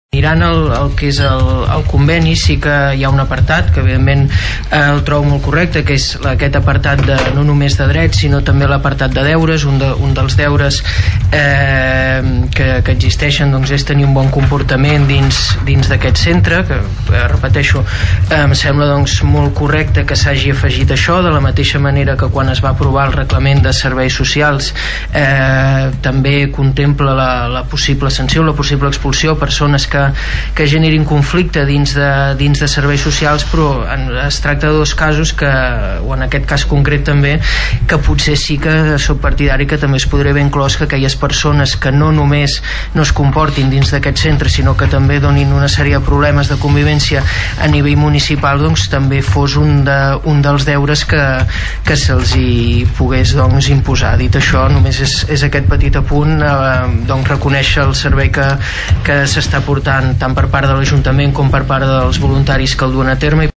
El regidor del PP, Xavier Martín, va votar a favor d’aprovar el servei i apuntava que, potser, es podria afegir al conveni del “De Tots” el deure de bona conducta fora del centre, i no només a dins.